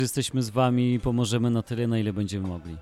Wójt gminy Rzekuń przekazał też Dolnoślązakom słowa wsparcia.